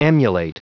Prononciation du mot emulate en anglais (fichier audio)
Prononciation du mot : emulate